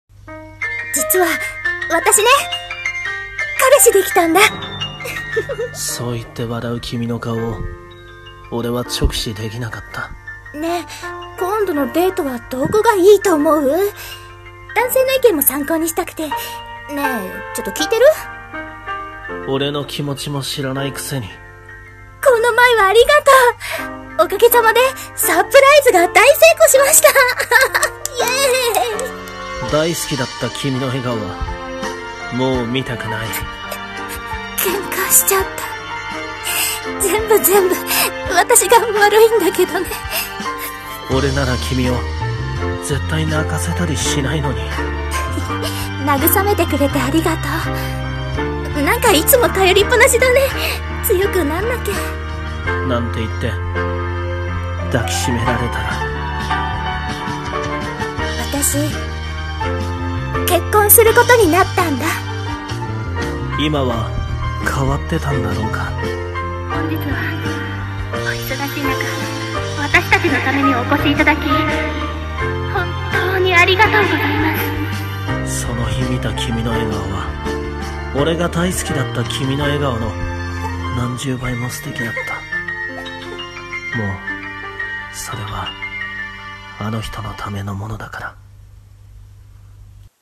【声劇台本】君の笑顔